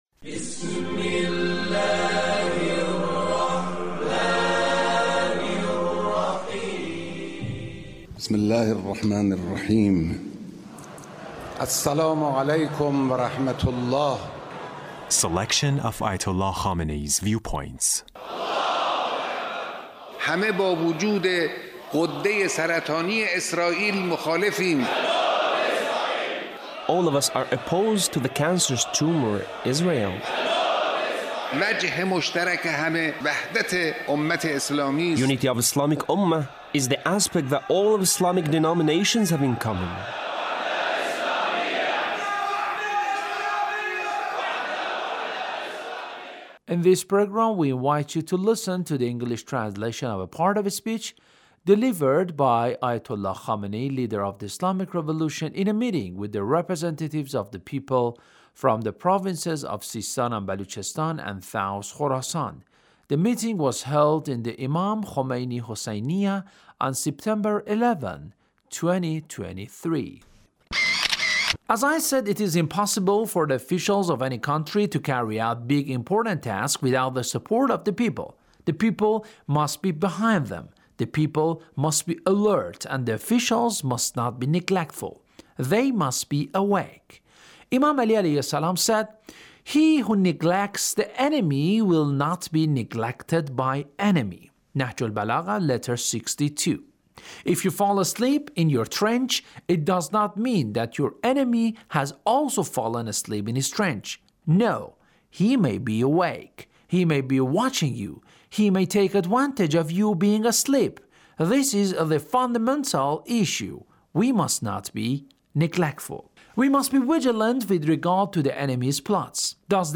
Leader's Speech (1837)